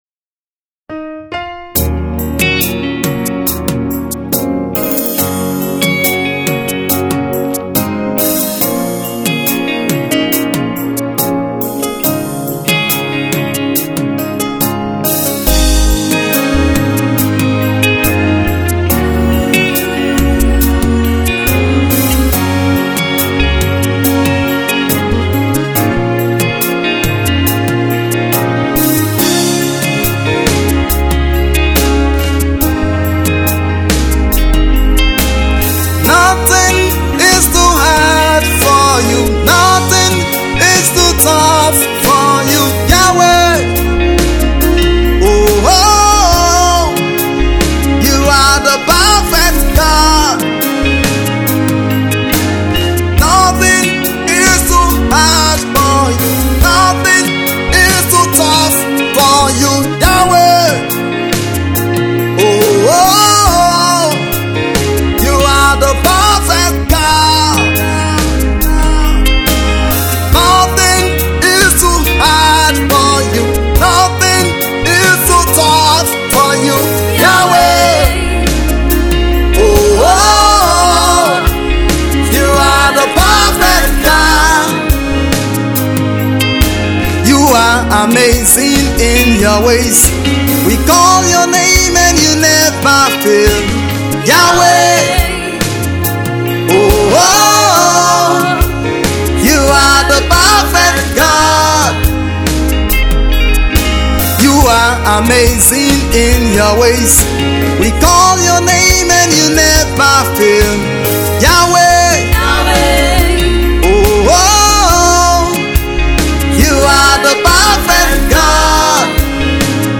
Gospel music minister
spirit filled and highly recommend to bless your soul.